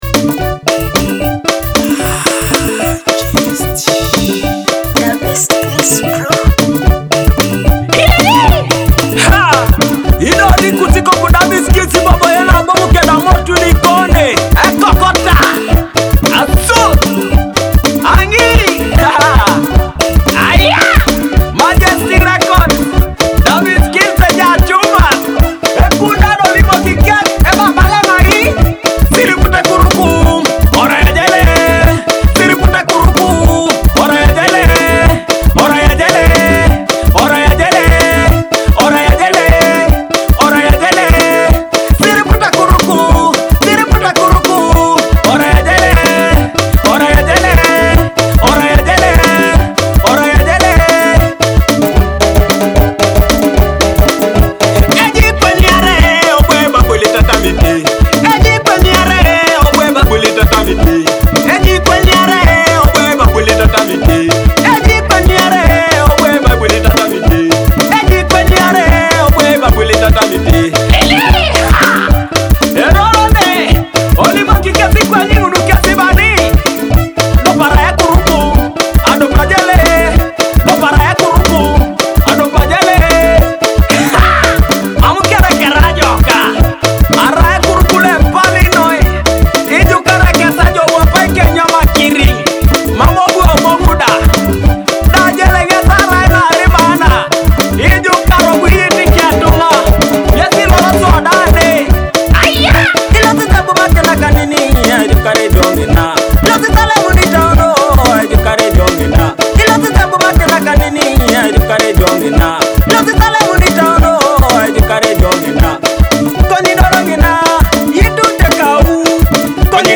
With its catchy, Teso-inspired rhythm and feel-good lyrics